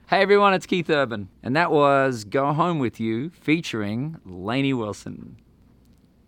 LINER Keith Urban (Go Home With You) 3